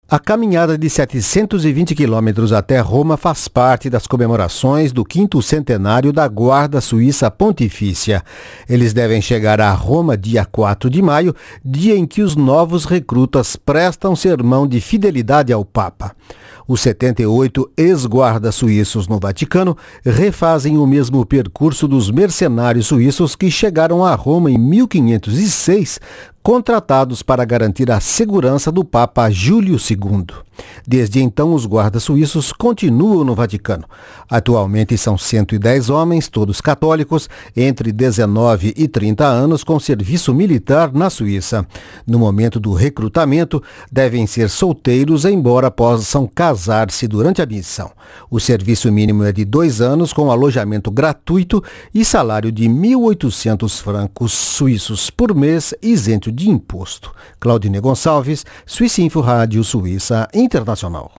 Os ex-guardas suíços foram recebidos com banda de música no castelo Sforzesco, no centro de Milao.